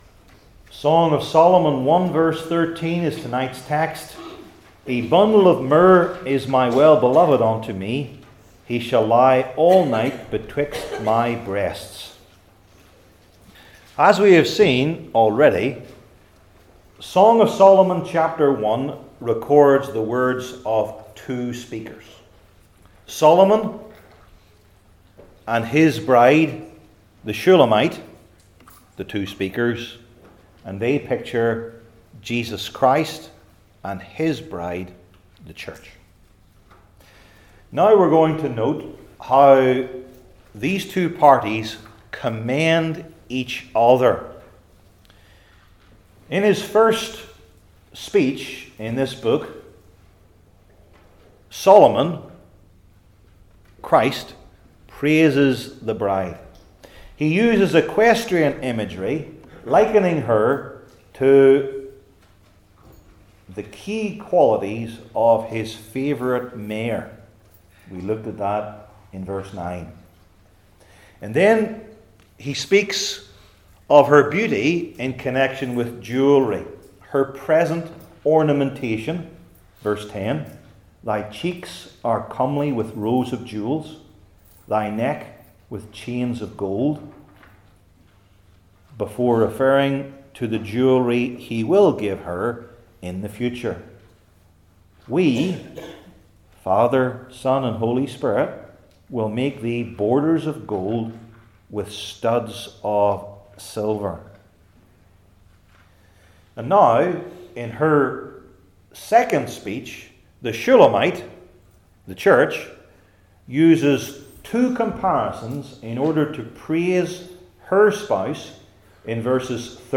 Old Testament Sermon Series I. The Meaning II.